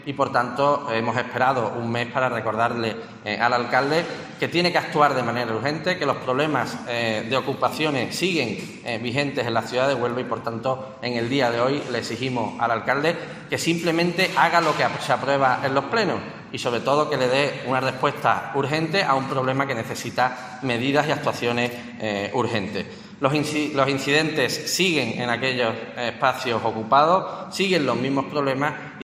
Guillermo García de Longoria, portavoz grupo municipal Ciudadanos